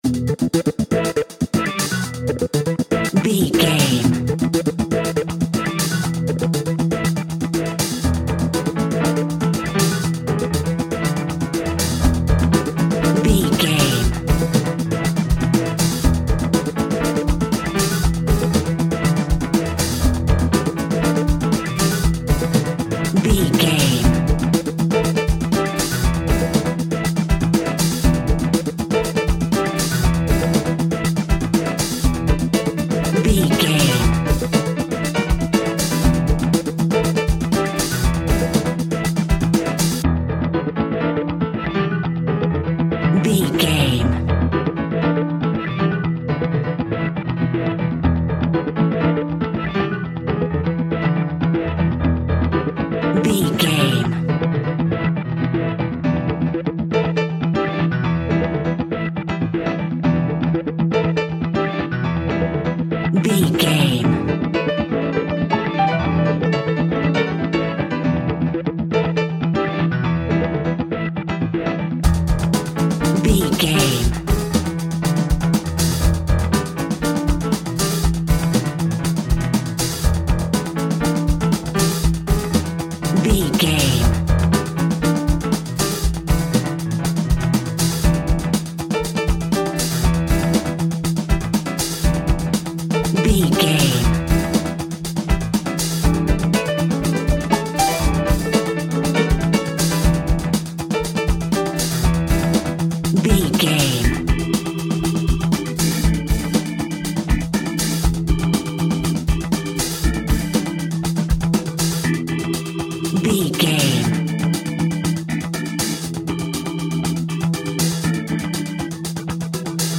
Hip Hop Grooving.
Aeolian/Minor
synth lead
synth bass
hip hop synths
electronics